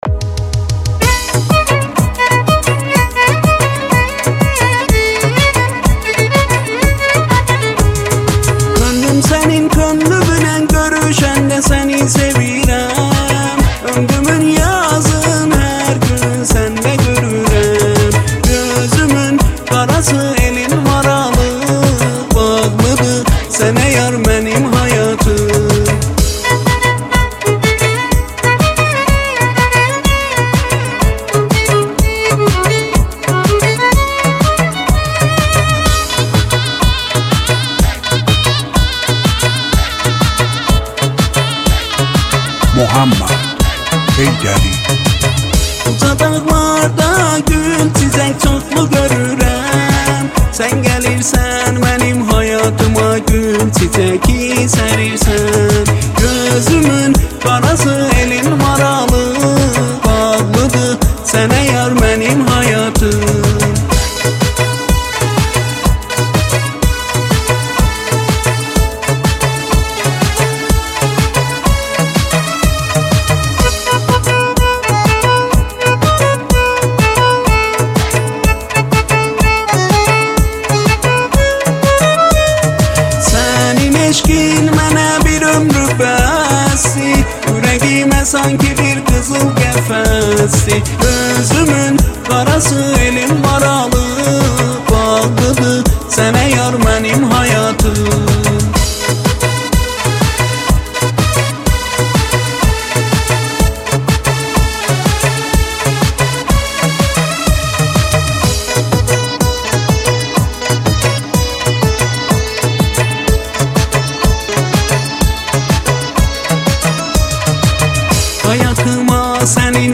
آهنگ جدید موزیک ترکی آذربایجانی